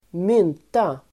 Uttal: [²m'yn:ta]